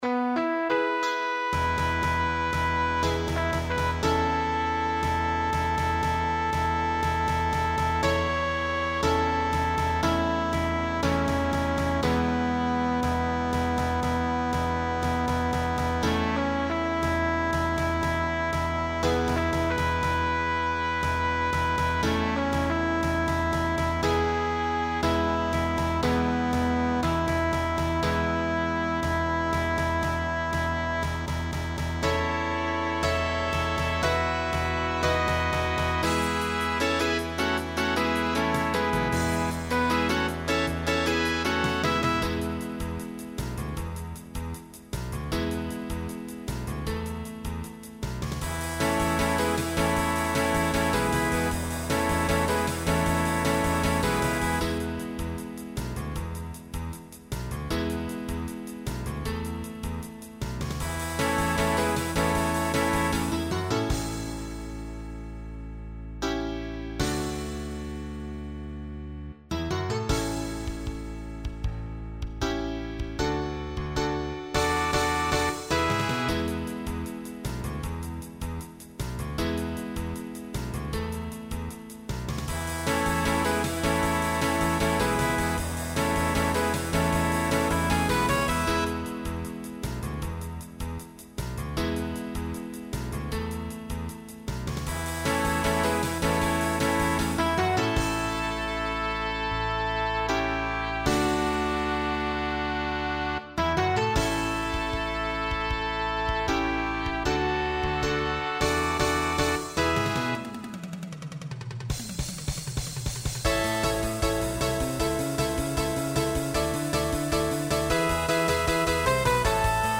Voicing SATB
Genre Pop/Dance , Rock